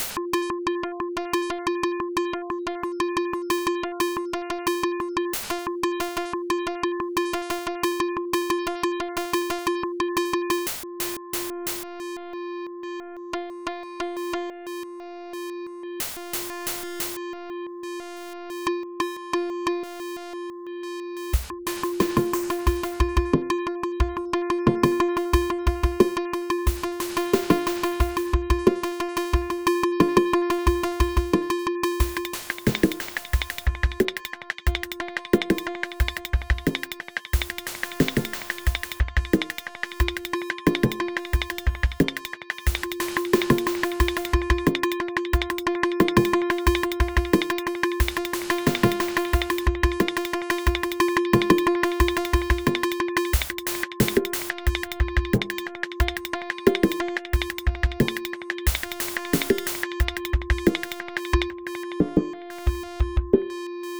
Pieza Intelligent dance music (IDM)
Dance
melodía
repetitivo
rítmico
sintetizador